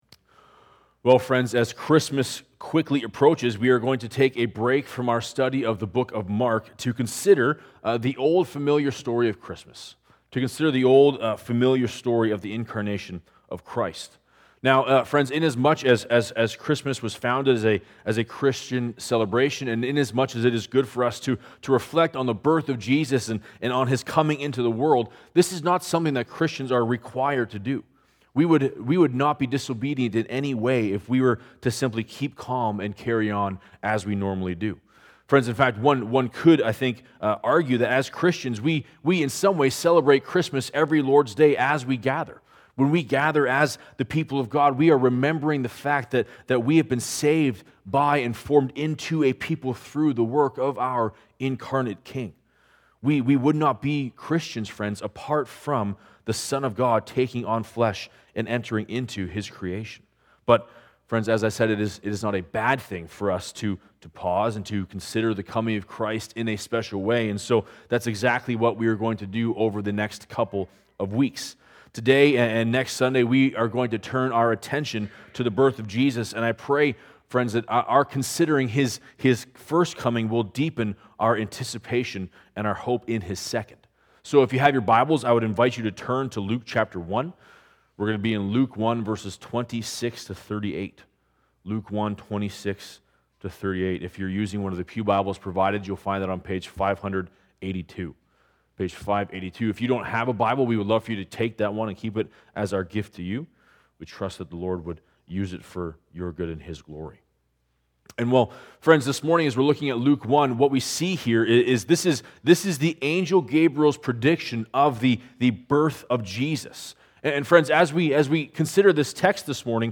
Download JSAC Sermons Sunday morning sermons from Jacqueline Street Alliance Church.